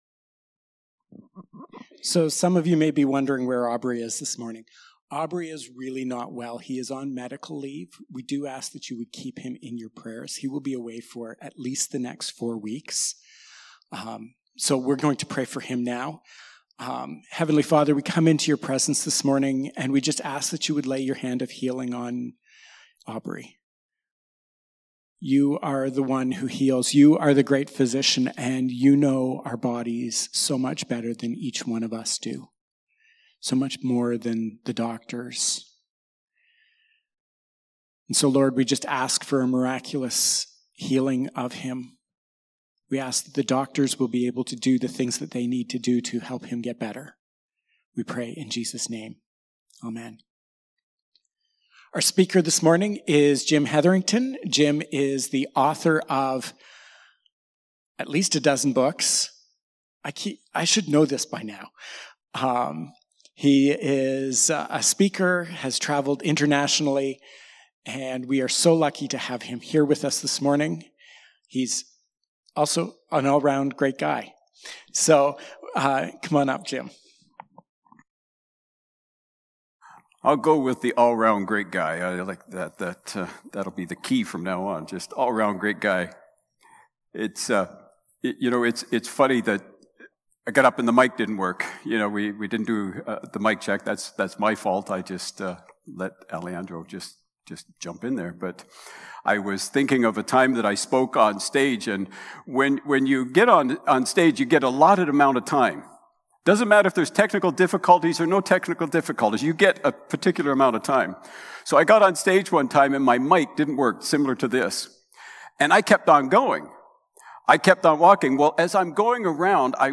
March-1-Sermon.mp3